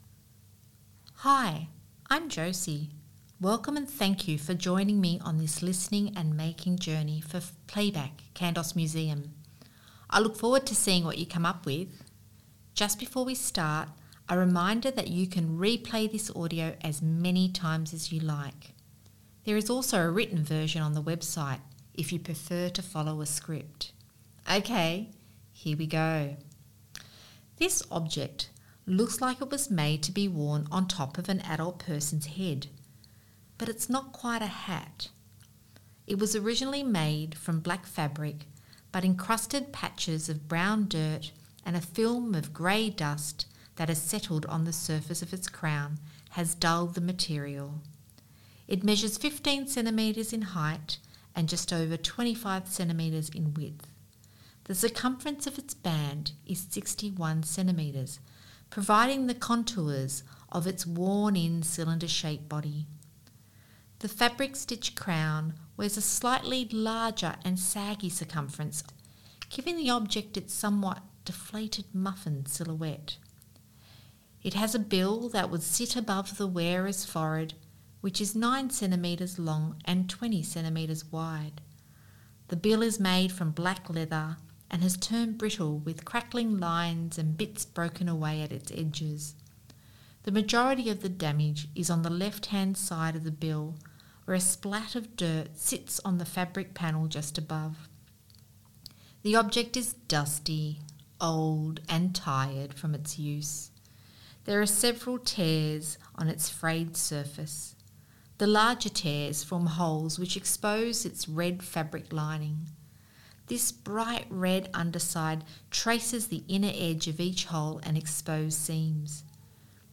Audio Narration and Transcript